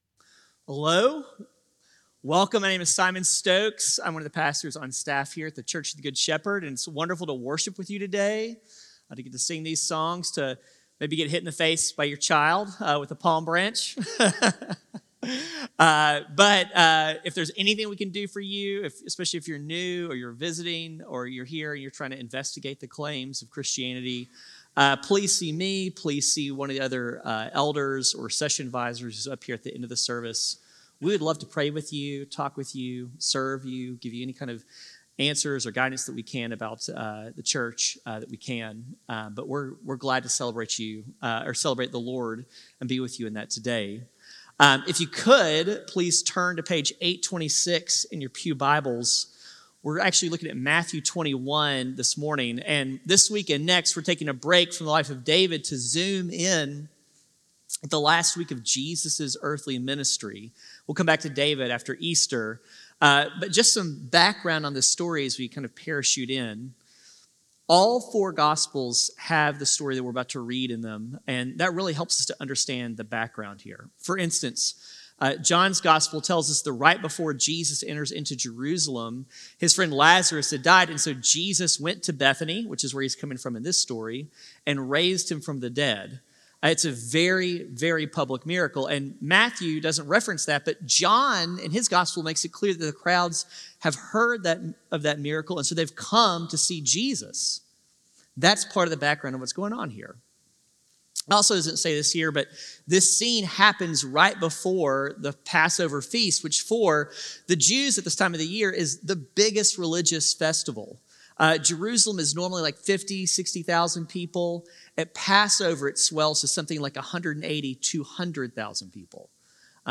CGS-Service-4-13-25-Audio-Podcast.mp3